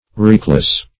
Wreakless \Wreak"less\, a.